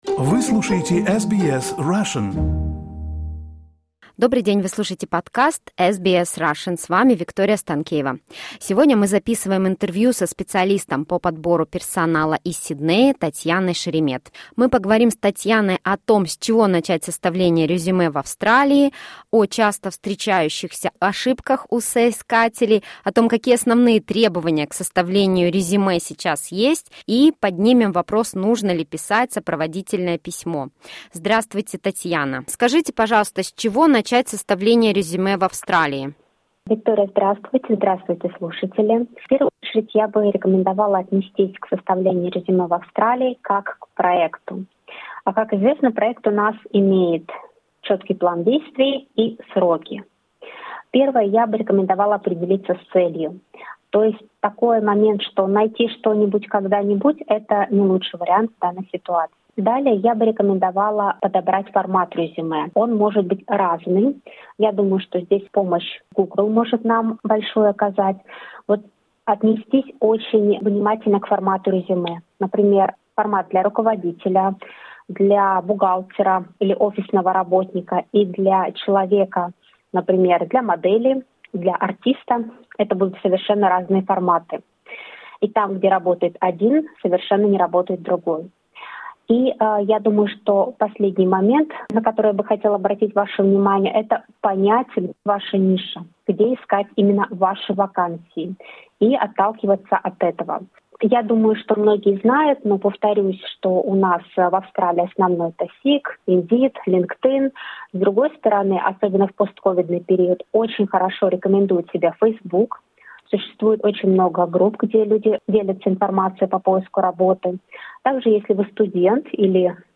В интервью она поделилась следующими практическими советами.